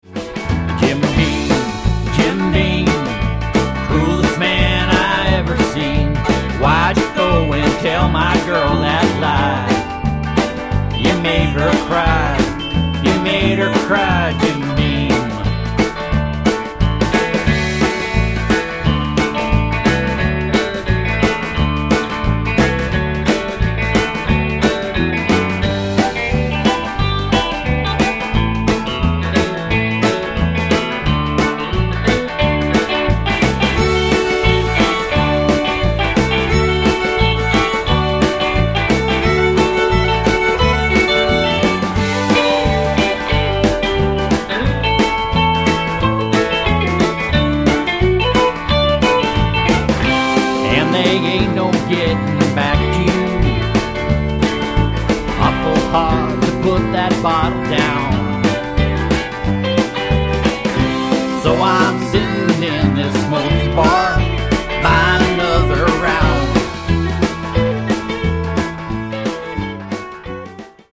The tracks were recorded primarily "live"
stripped down and hard-rocking.